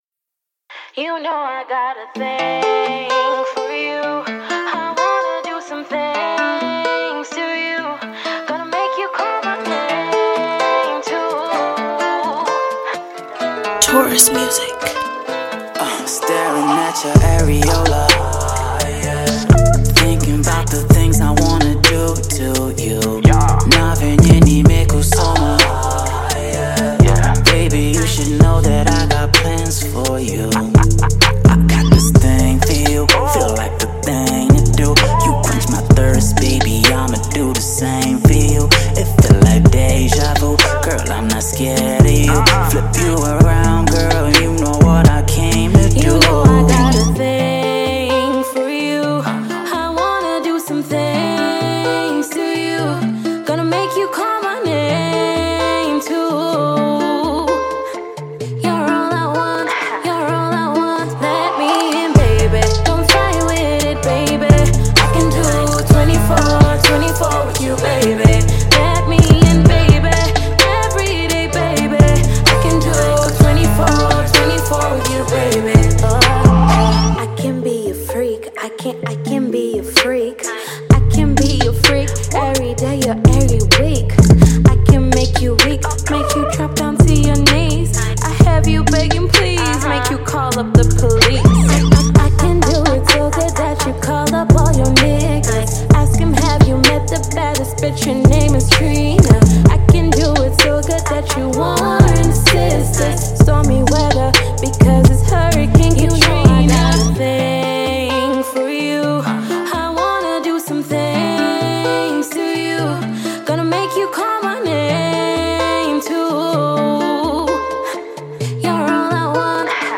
romantic duet